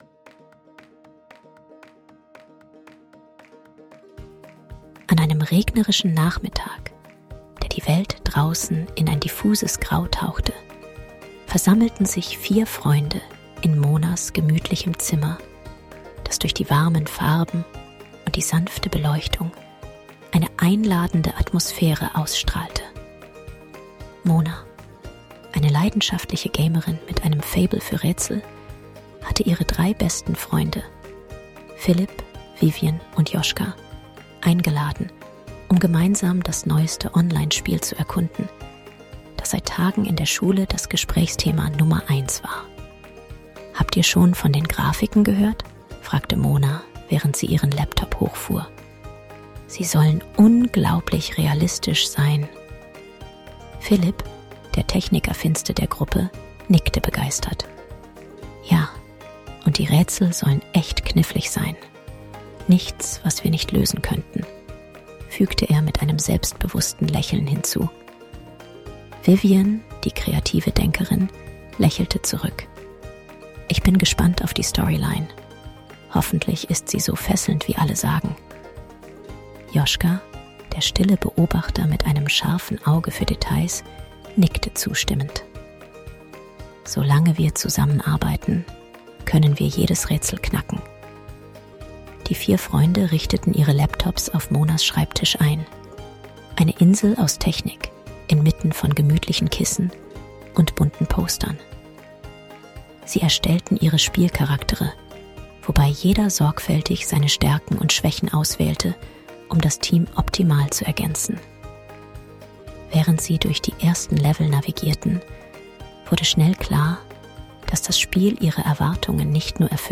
Taucht ein in "Das Rätsel des Spiels", ein spannendes Hörspiel über Freundschaft, Teamarbeit und die unbegrenzten Möglichkeiten der Spielewelt.